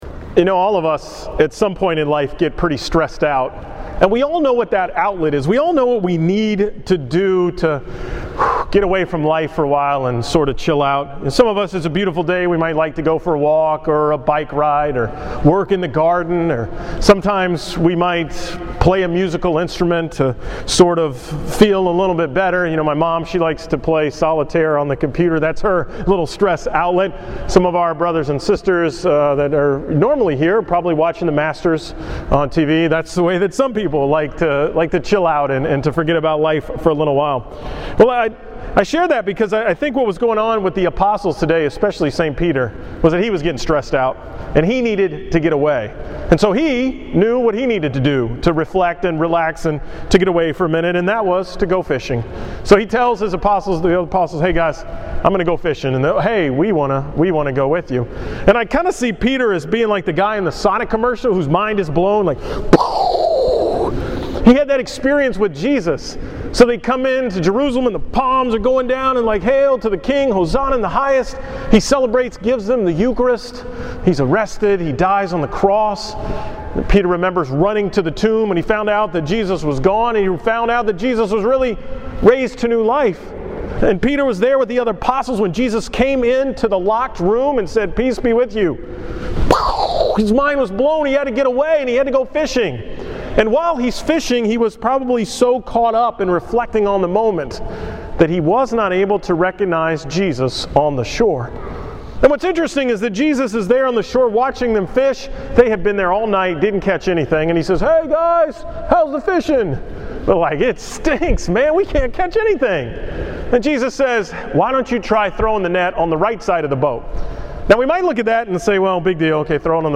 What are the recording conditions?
From the 5 pm Mass at St. Mary’s on Sunday, April 14th